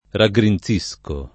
raggrinzare v.; raggrinzo [raggr&nZo] — anche raggrinzire: raggrinzisco [